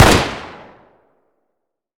fire.wav